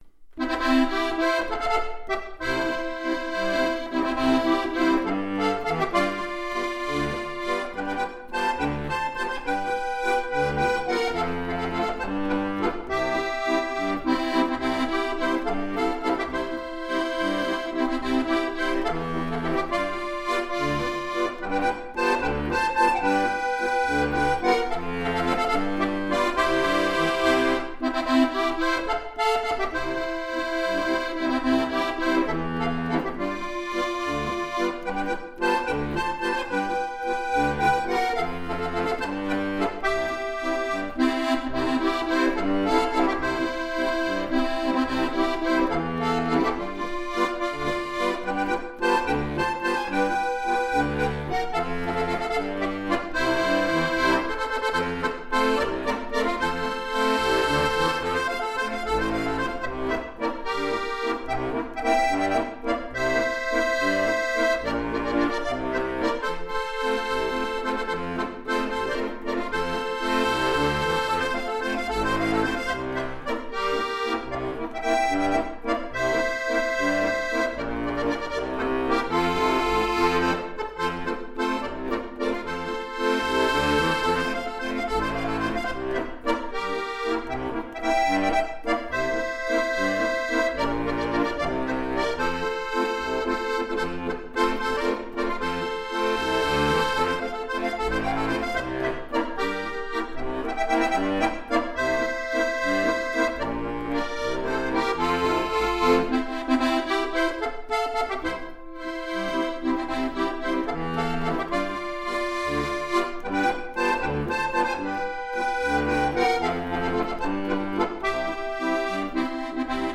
ziach2.mp3